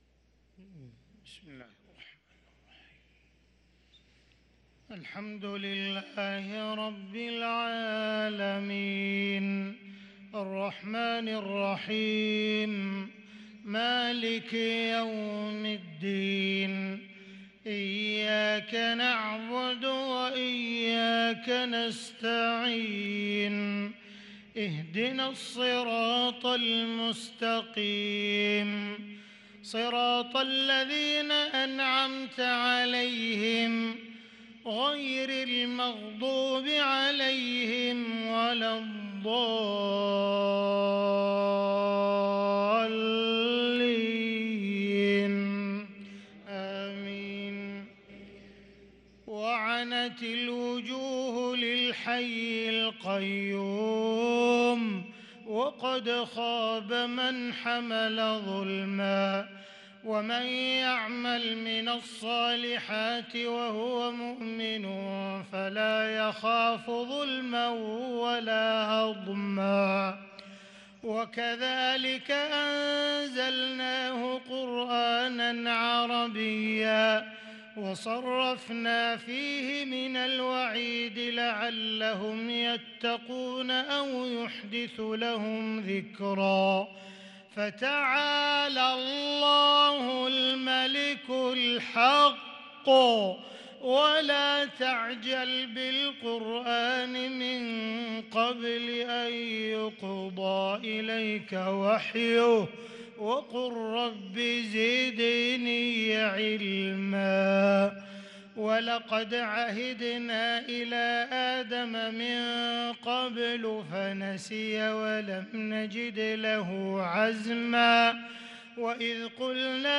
صلاة الفجر للقارئ عبدالرحمن السديس 20 ربيع الآخر 1444 هـ
تِلَاوَات الْحَرَمَيْن .